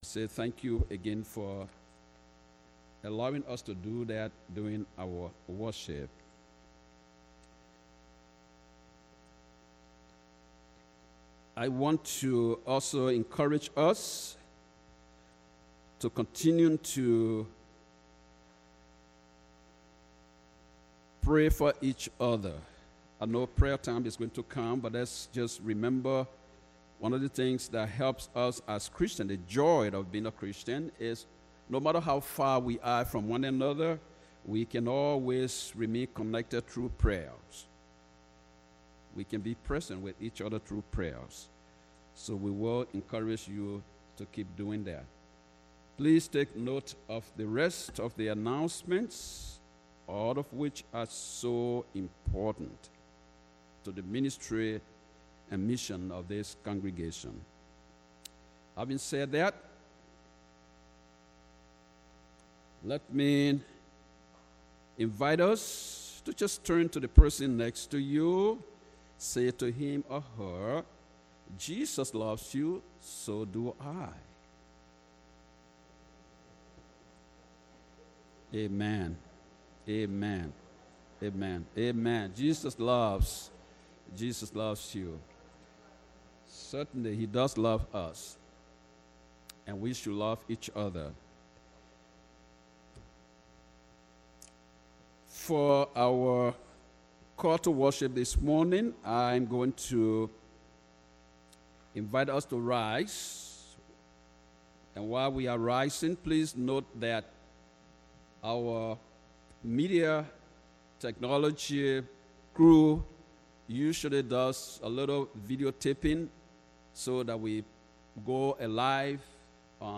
First United Methodist Church Racine Archived Sermons 8/18 - 7/19